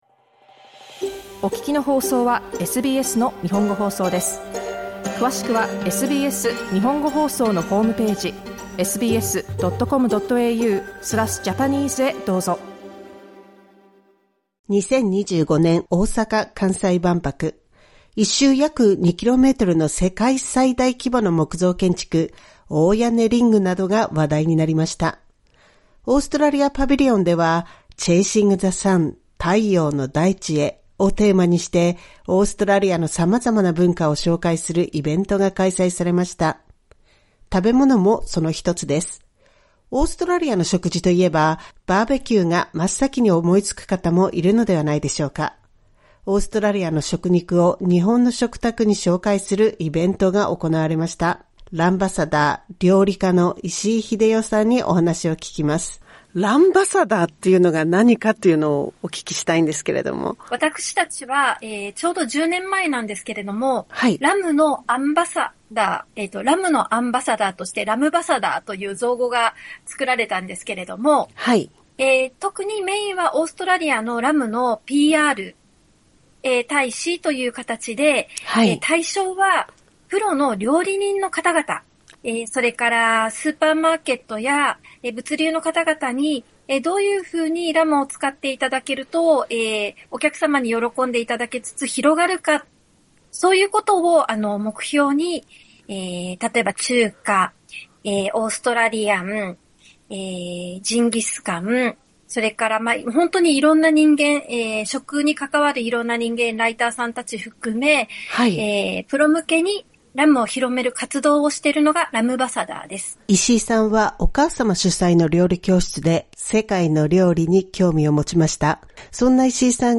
インタビューでは、大阪・関西万博でのイベントの様子、日本人にとってのラムとはなどを聞いています。